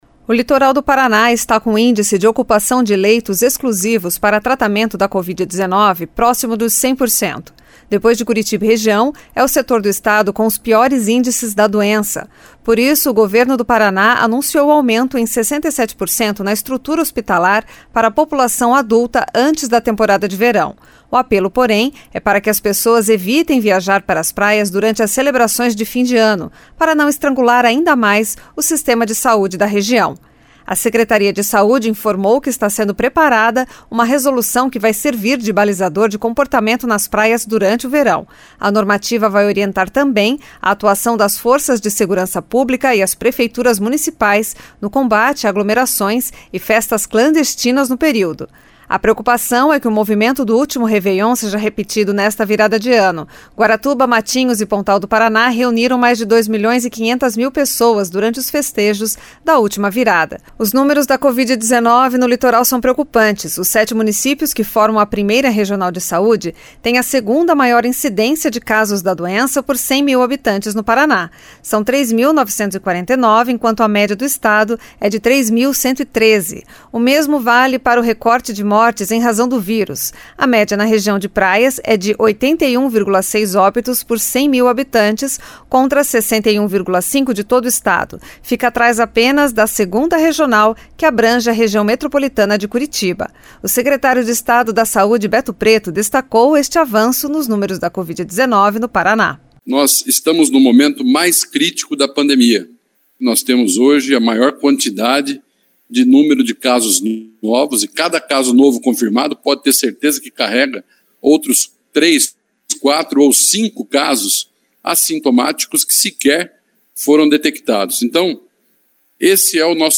O secretário de Estado da Saúde, Beto Preto, destacou este avanço nos números da Covid-19 no Paraná.